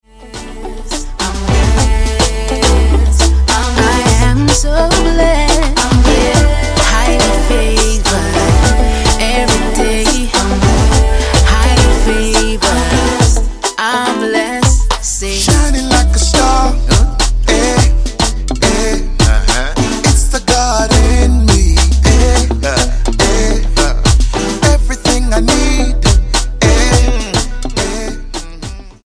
STYLE: Gospel
full of clever jazz chords and an auto-tuned vocal